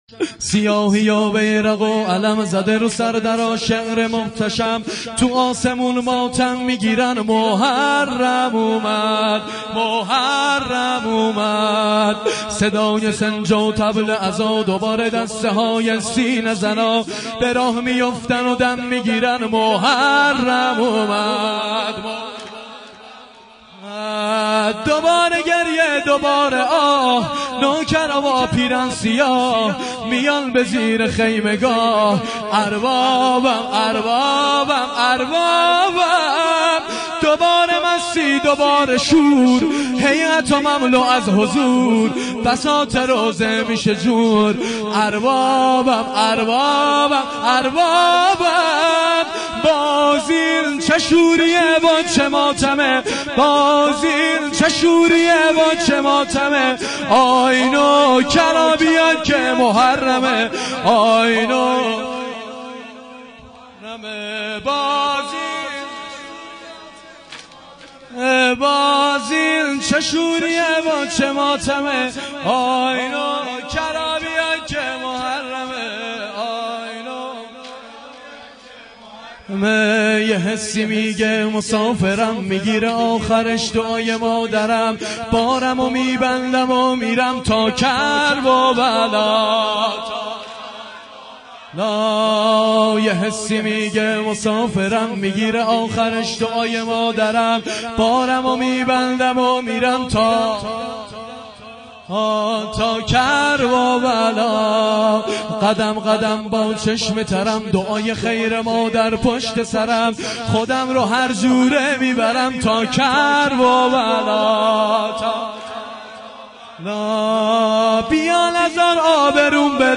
شب اول محرم ۱۴۴۱